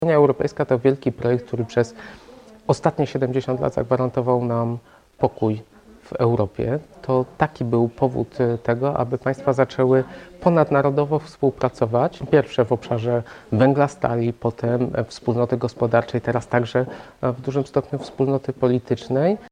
To spotkanie było okazją, aby o Unii Europejskiej porozmawiać w kontekście szerszym niż tylko w odniesieniu do chodnika powstającego w jednej czy drugiej miejscowości – podkreśla starosta krapkowicki Maciej Sonik.